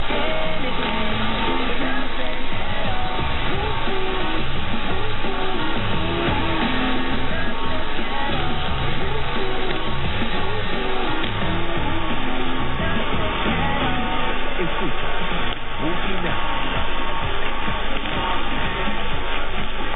Tässä näyte Boogie Nights -kuulutuksesta 22.10 aamulta 1250:ltä: